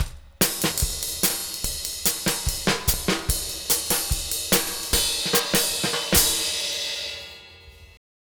Twisting 2Nite 5 Drumz.wav